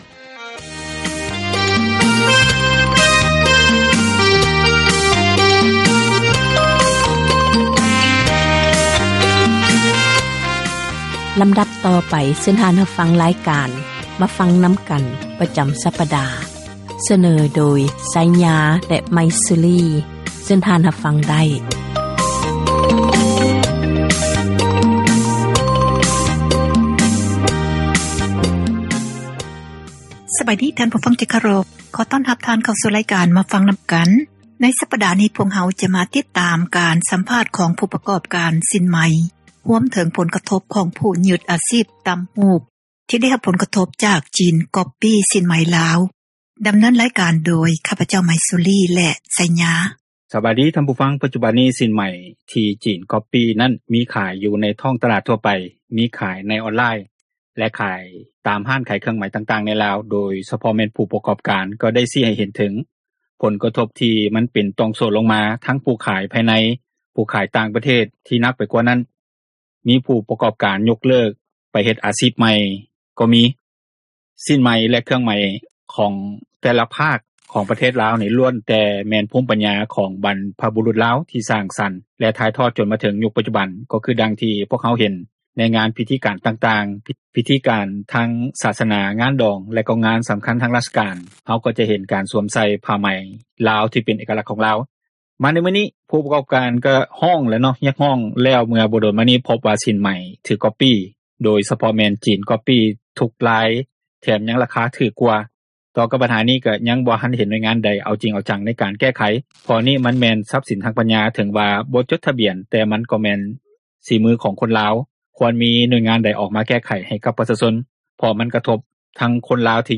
ຜູ້ສົ່ງອອກສິ້ນ ກໍສະດຸດ ຍ້ອນວ່າສິ້ນໄໝ ທີ່ຈີນ ກ໊ອບປີ້ ໄປນັ້ນຕີຕລາດ ດ້ວຍລວດລາຍ ທີ່ຄືກັບສິ້ນໄໝລາວ ແລະ ຣາຄາຖືກ ກວ່າທົບເຄີ່ງ. ຣາຍການ ມາຟັງນໍາກັນ ມີຄຳເຫັນ ແລະ ສັມພາດ ເຈາະເລິກ ມາສເນີ ຕໍ່ກັບບັນຫານີ້.